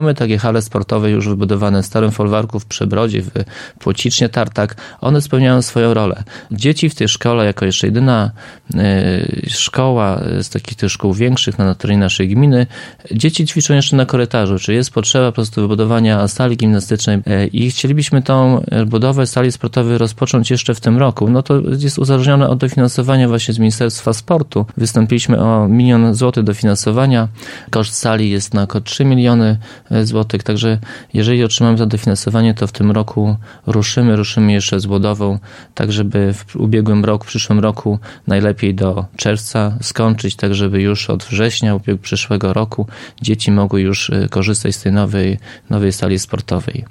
– Podobne hale powstały już w Przebrodzie, Płocicznie i w Starym Folwarku – mówi Zbigniew Mackiewicz, wójt gminy Suwałki.